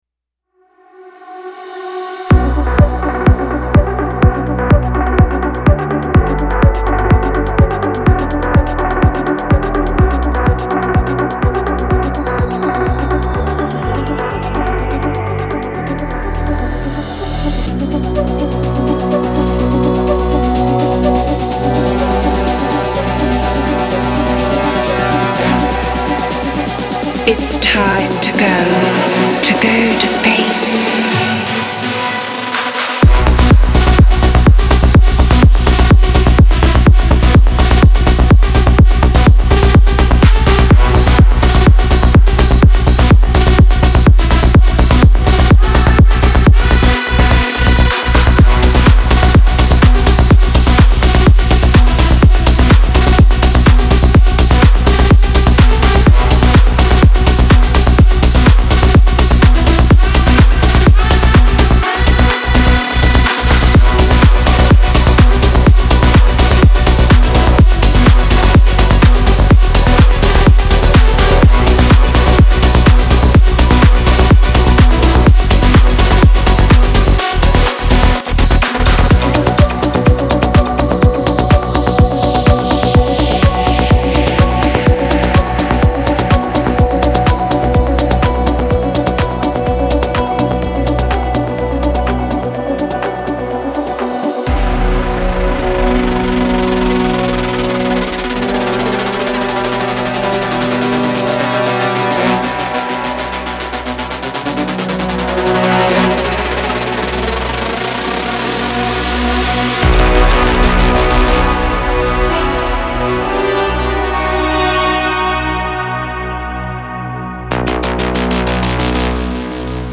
• Жанр: Dance, Electronic